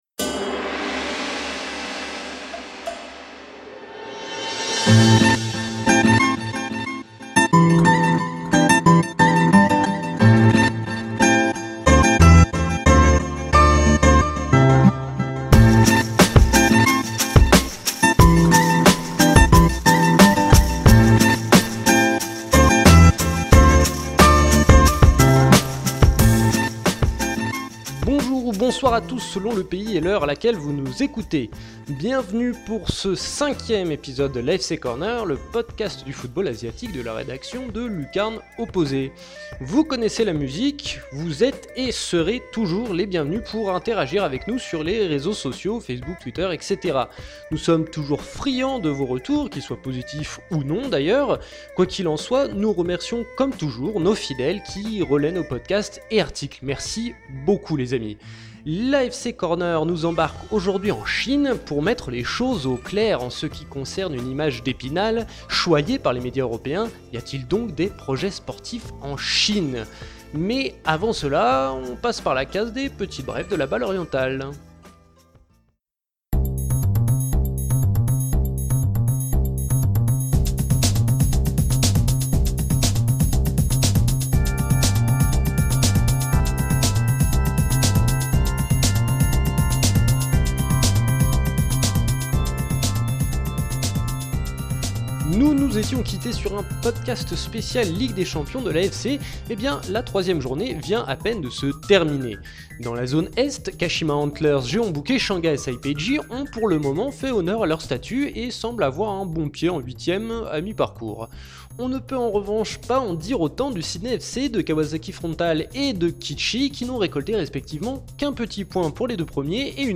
Nouveau rendez-vous de l'AFC Corner, l'émission qui décrypte le football asiatique. Au programme cette semaine une grande question, qui anime bien des débats, les projets sportifs existent-ils en Chinese Super League ?